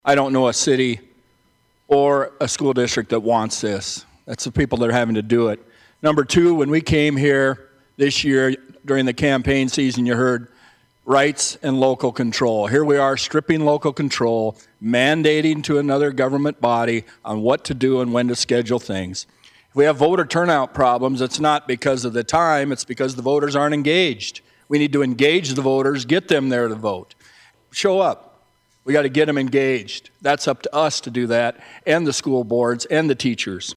Senator Randy Deibert of Spearfish says there are multiple reasons to oppose the bill…